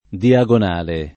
[ dia g on # le ]